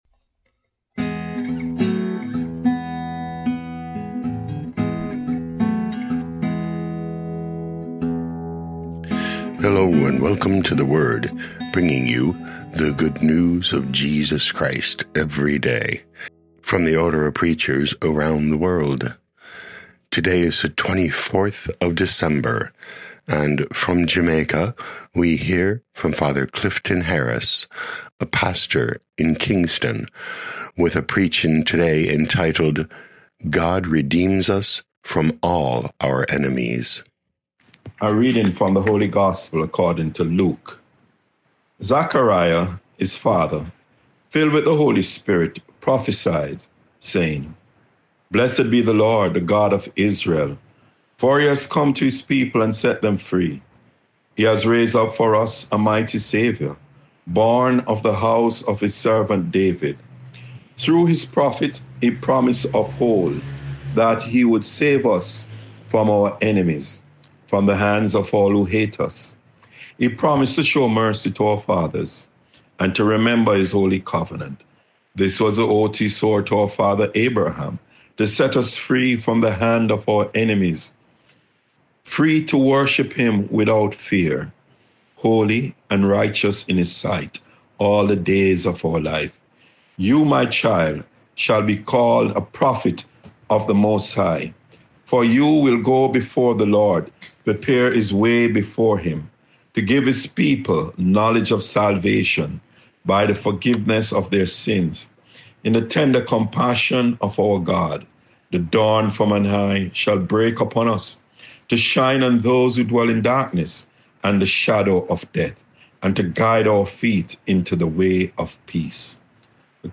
24 Dec 2025 God Redeems Us from All Our Enemies Podcast: Play in new window | Download For 24 December 2025, December 24, based on Luke 1:67-79, sent in from Kingston, Jamaica.
OP Preaching